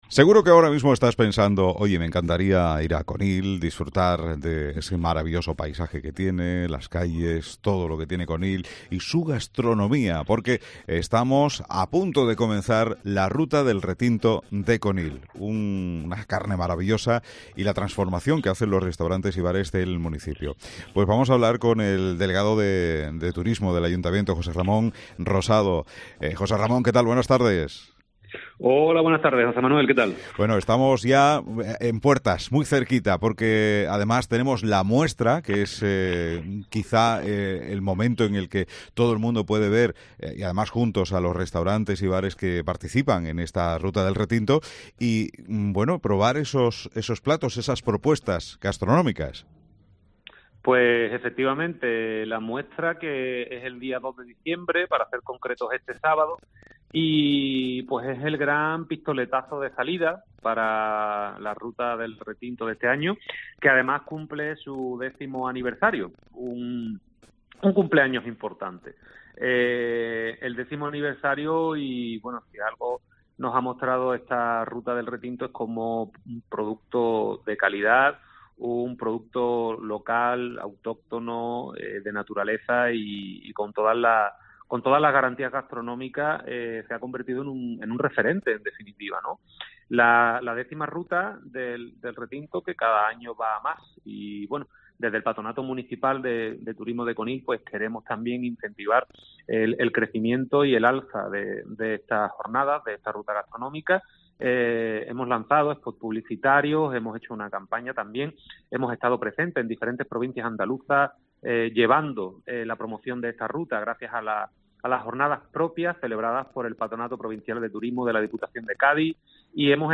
José Ramón Rosado, Delegado de Turismo del Ayuntamiento de Conil cuenta algunos detalles de esta ruta.